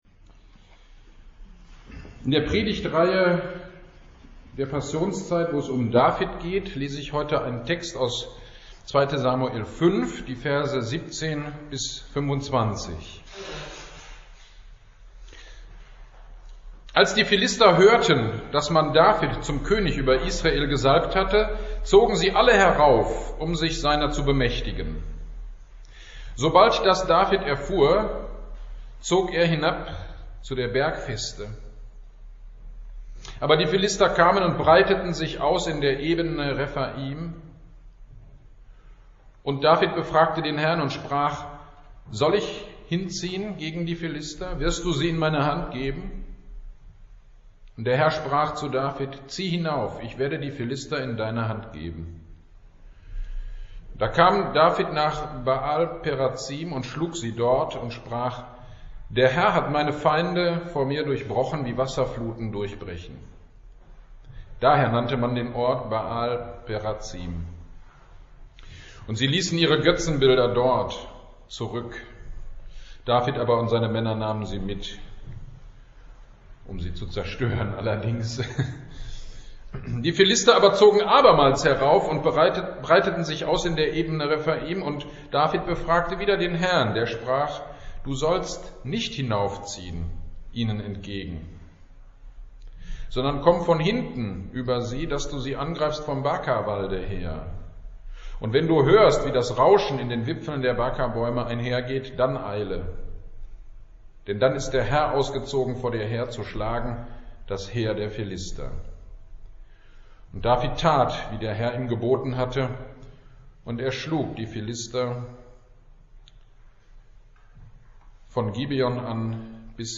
GD am 20.03.22 Predigt zu 2. Samuel 5.17-25 In der Not - Kirchgemeinde Pölzig